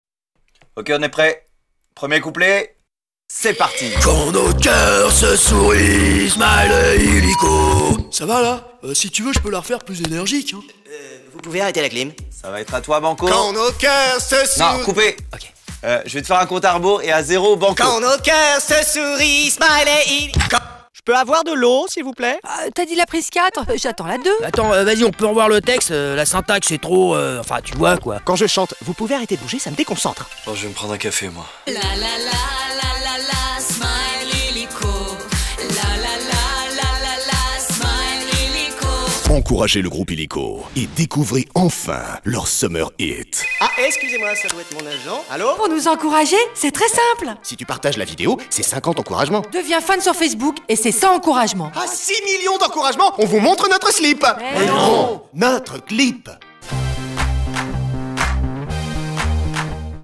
Voix-off Illiko épisode 3 : Grosse voix, humour et personnages fous
Drôle et fou + voix grave de fin.
Spot web pour le jeu Illiko de la FDJ.
Plusieurs personnages complètement fous  Solitaire, Banco et Vegas plus grosse voix grave de fin.
De la voix médium à la voix très grave, en passant par des personnages drôles et fous, ce fut un véritable plaisir de donner vie à ce spot web.
Pour Illiko, il fallait une voix capable de transmettre l’excitation, l’amusement et la folie du jeu, mais aussi une voix grave et profonde pour marquer les esprits en fin de spot.